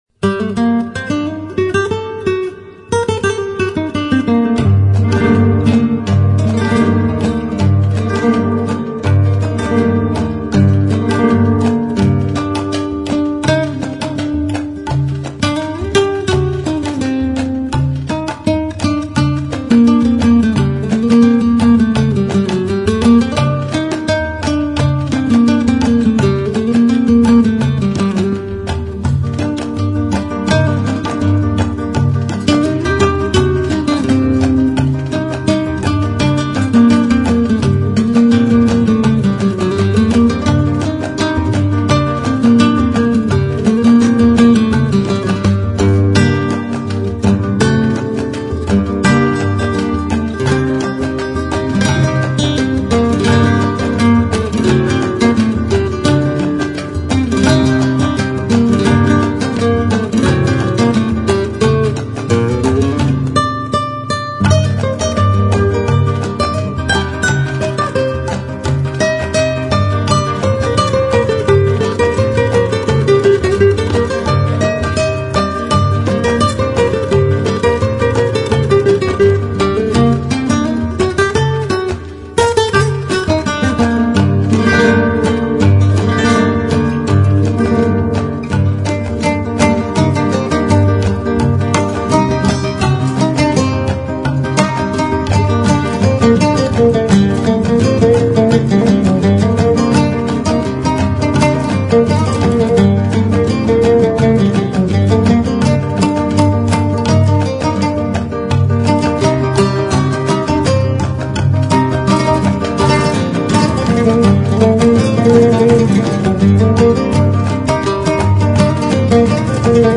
Violao Flamenco Classico